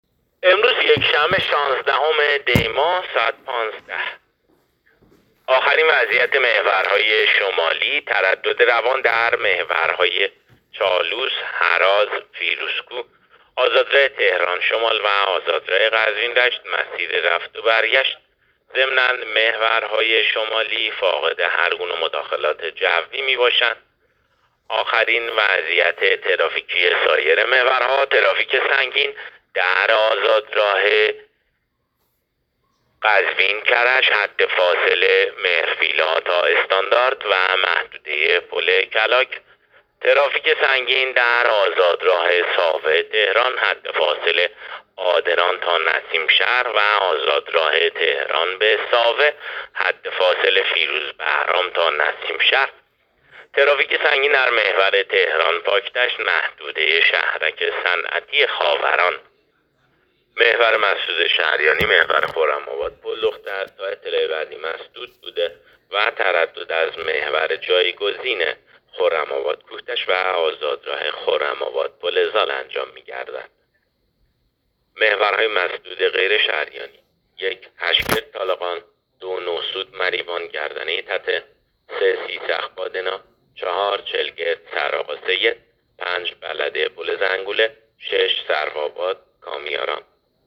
گزارش رادیو اینترنتی از آخرین وضعیت ترافیکی جاده‌ها تا ساعت ۱۵ شانزدهم دی؛